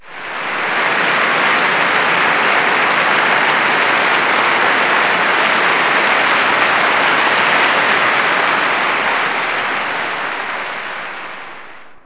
clap_041.wav